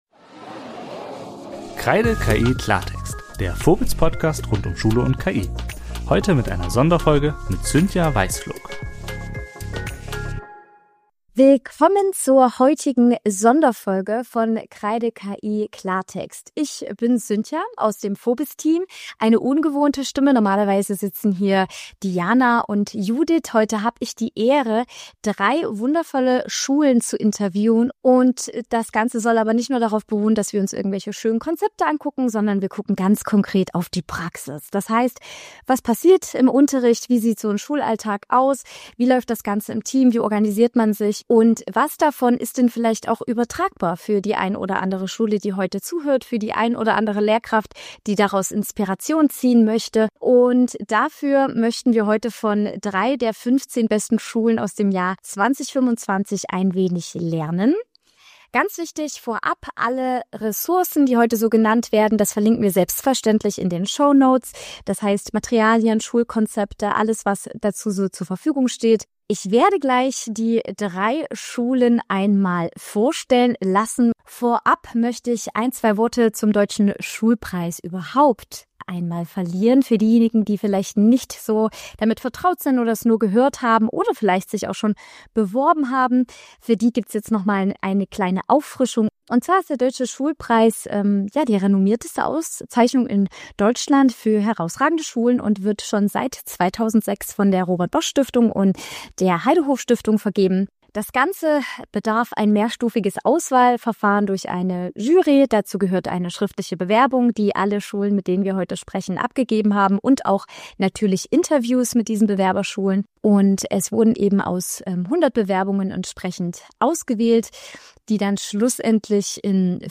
Beschreibung vor 2 Monaten Diese Folge ist etwas Besonderes: Wir sprechen mit drei Schulen, die für den Deutschen Schulpreis nominiert wurden (darunter auch die Gewinnerschule 2025), über ihre Erfahrungen aus der Praxis: Was hat Schulentwicklung bei euch wirklich vorangebracht?